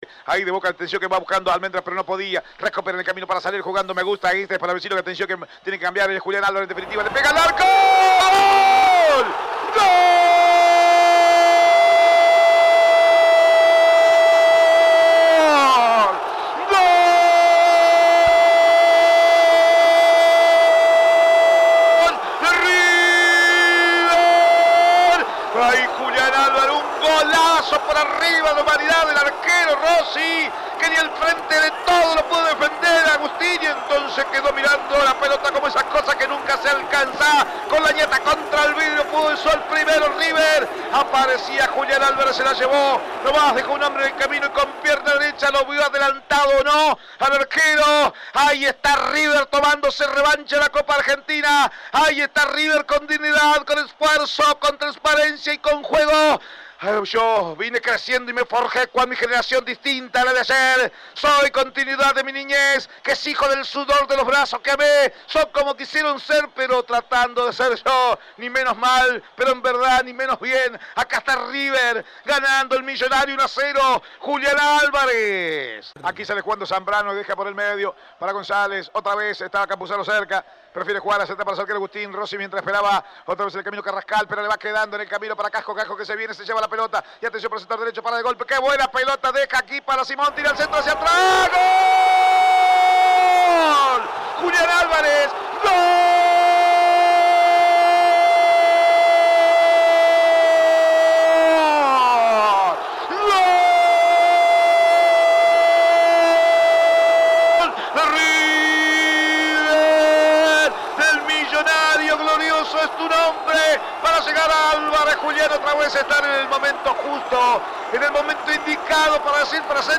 Escuchá los goles de Álvarez en la voz